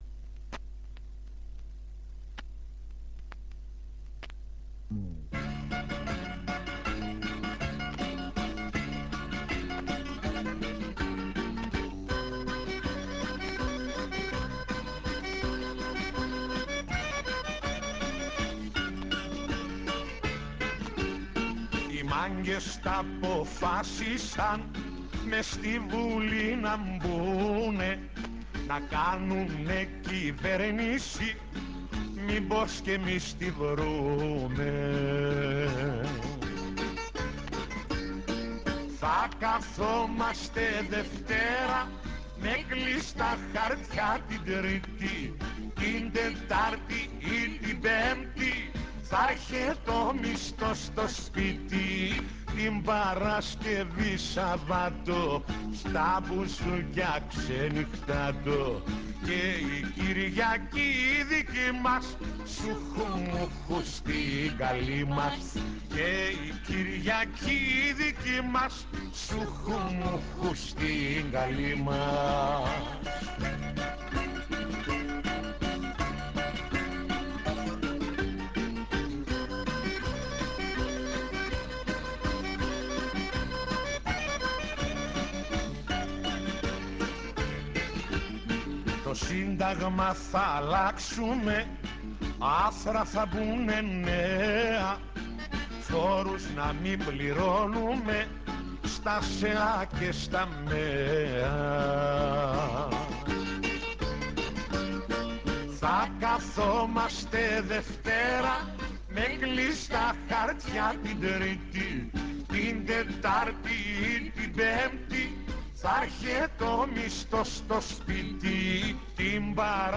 "Laikon"